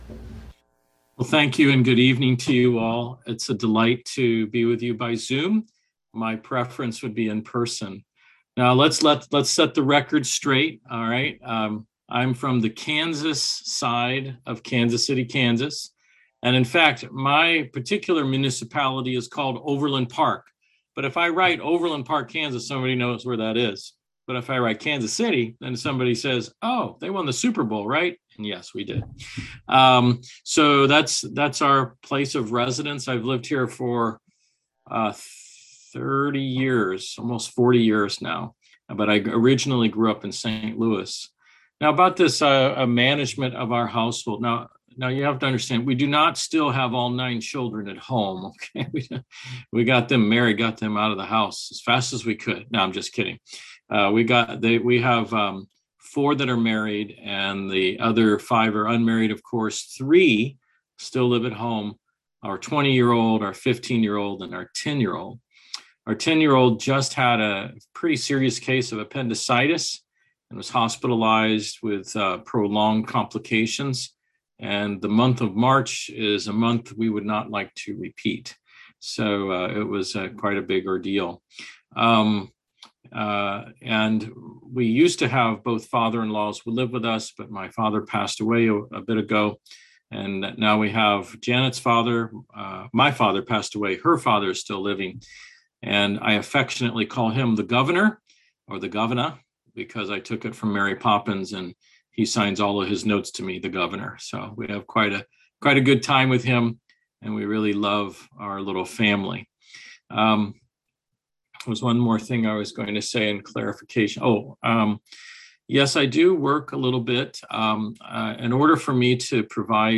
Series: Easter Conference Service Type: Seminar Topics: Attributes of God